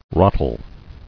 [ra·tel]